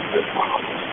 EVP's